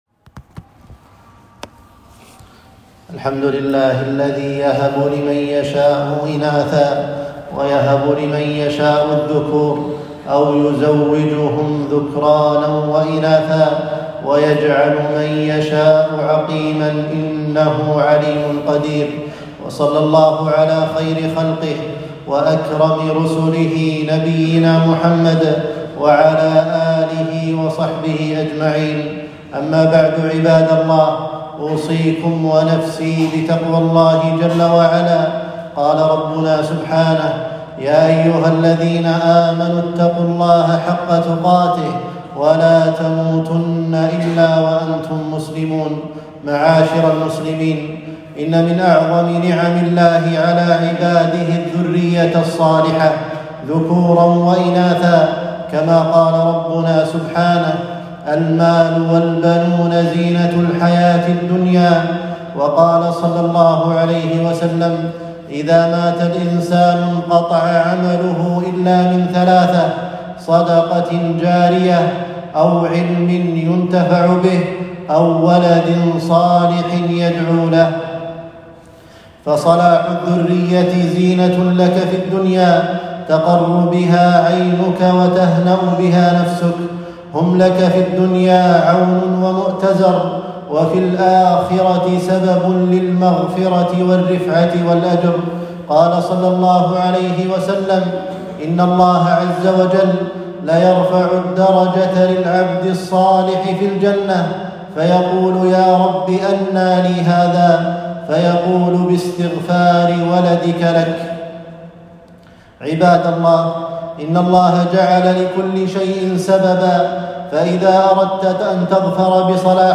خطبة - الأسباب الشرعية في صلاح الذرية